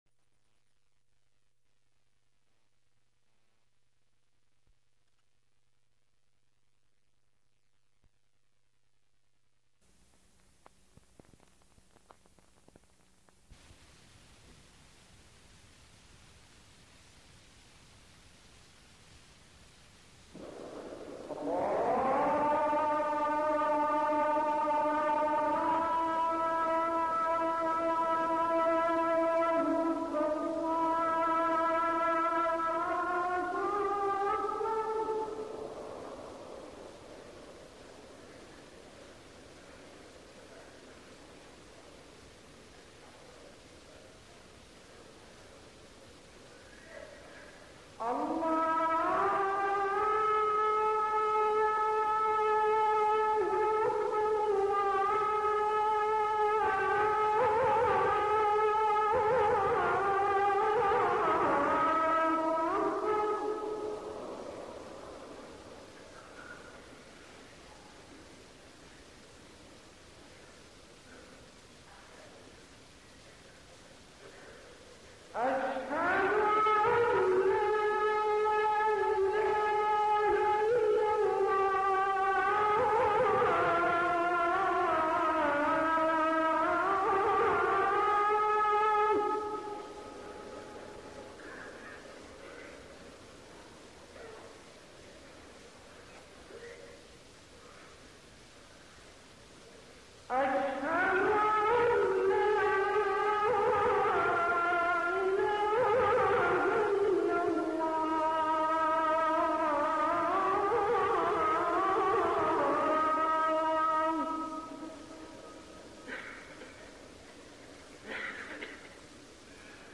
أذان
المكان: المسجد النبوي الشيخ